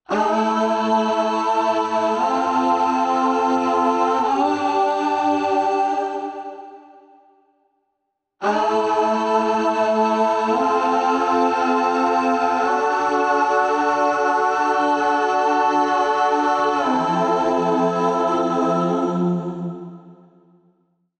Under Water on BG Vocals